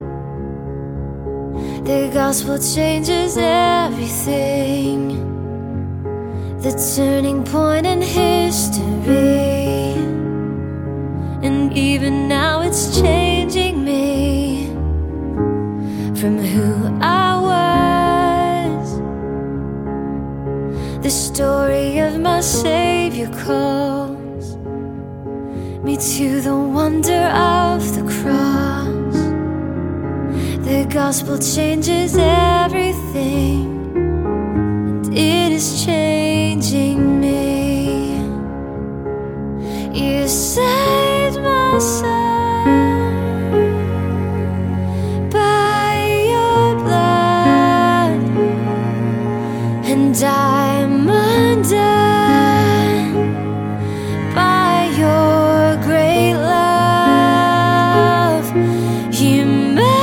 • Sachgebiet: Pop